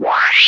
ui_open_tcd.wav